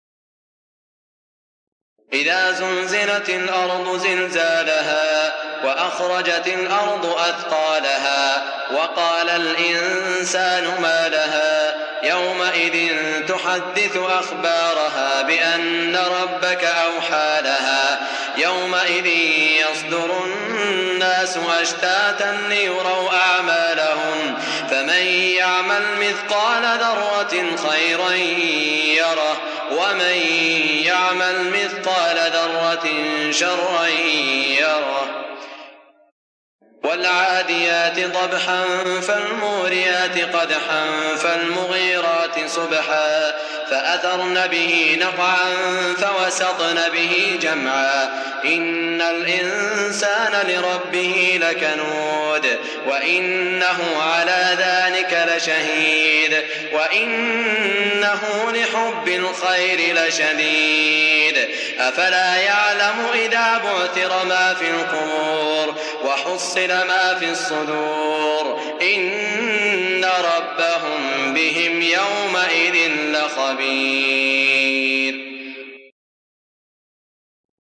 سورتي الزلزلة و العاديات من عام ١٤٠٨ | بمدينة الرياض > الشيخ سعود الشريم تلاوات ليست من الحرم > تلاوات وجهود أئمة الحرم المكي خارج الحرم > المزيد - تلاوات الحرمين